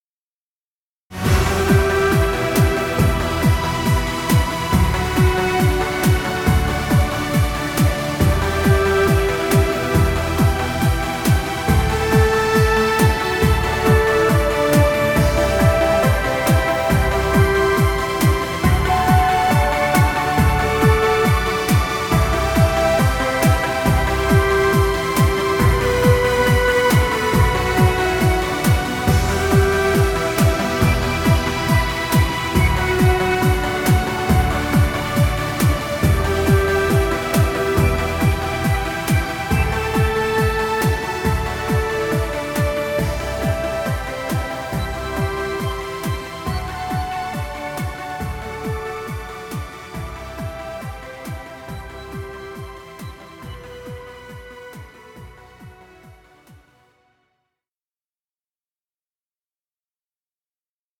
Dance music.